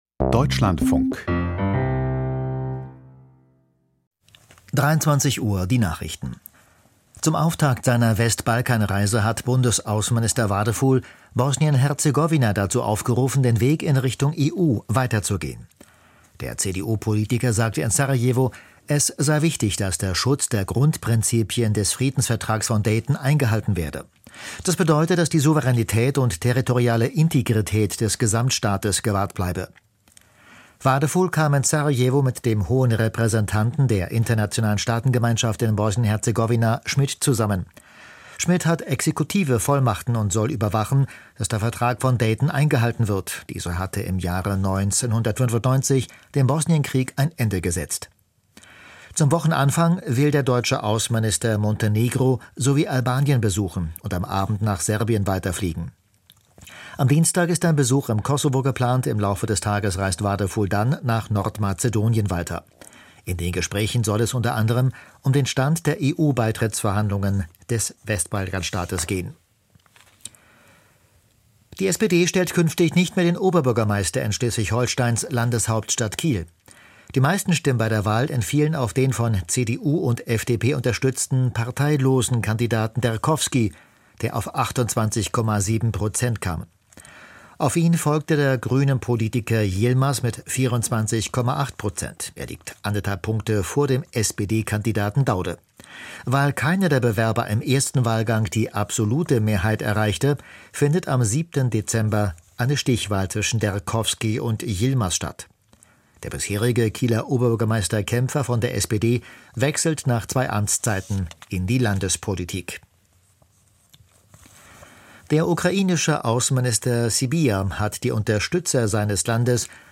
Die Nachrichten vom 16.11.2025, 23:00 Uhr
Aus der Deutschlandfunk-Nachrichtenredaktion.